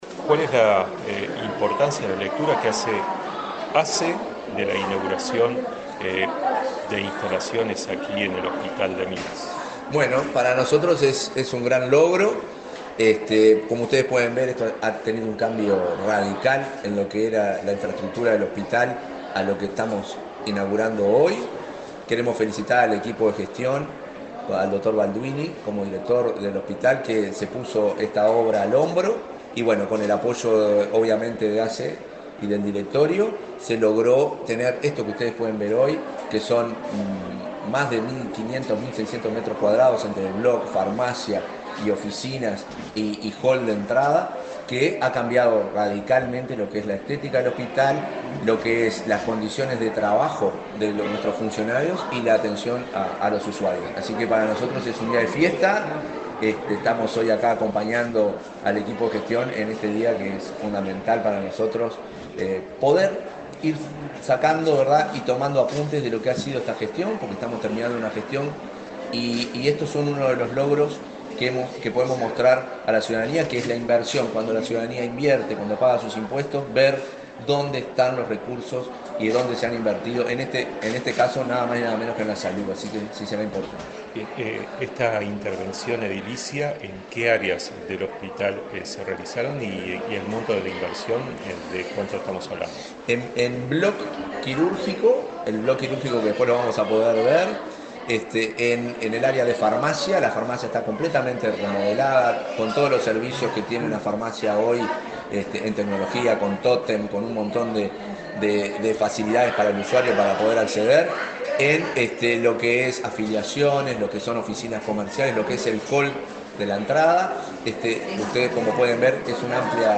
Entrevista al presidente de ASSE, Marcelo Sosa
Entrevista al presidente de ASSE, Marcelo Sosa 19/11/2024 Compartir Facebook X Copiar enlace WhatsApp LinkedIn La Administración de Servicios de Salud del Estado (ASSE) inauguró obras de remodelación en el área administrativa y en la farmacia del hospital departamental de Lavalleja. El presidente de ASSE, Marcelo Sosa, dialogó con Comunicación Presidencial, acerca de la importancia de estas reformas.